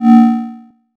kenney_sci-fi-sounds
forceField_002.ogg